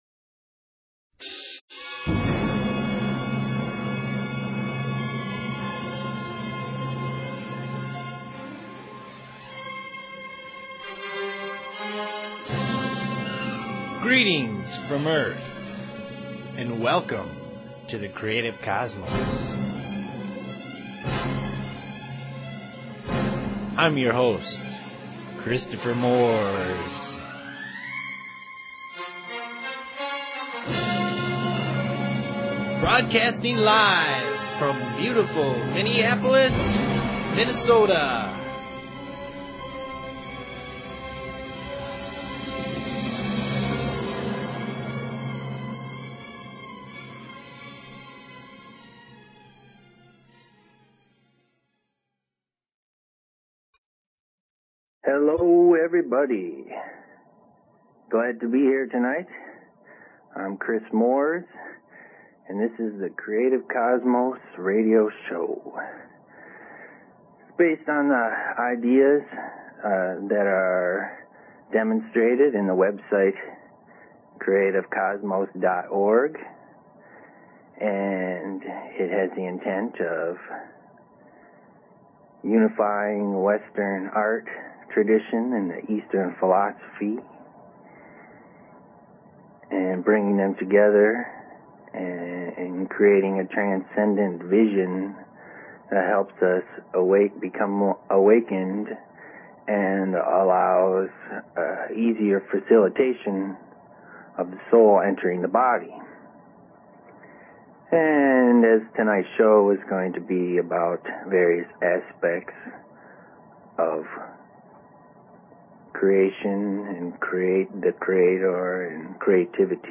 Zen, Chakras, Dreams, Astral Plane, Aliens, Spirit Communication, Past Lives, and Soul Awakening are all in play. There are no boundaries as the conversation goes where it needs to in the moment.